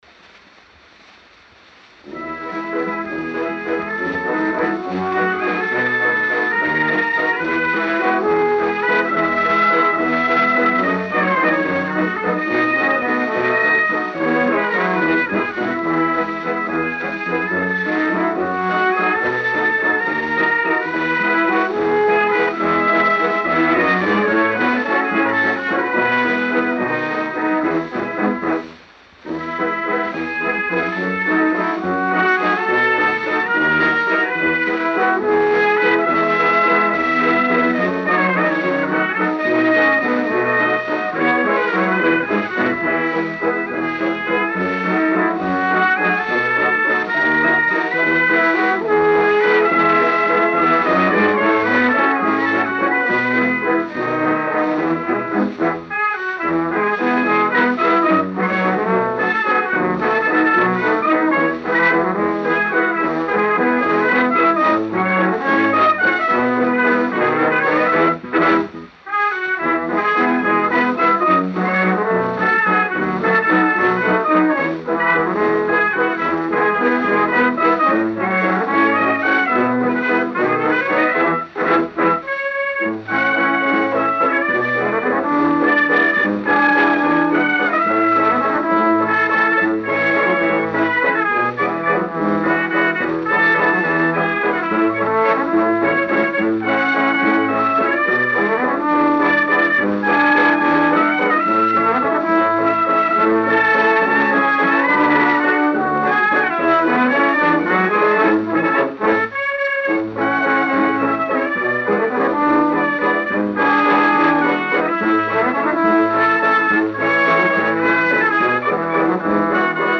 Gênero: Valsa.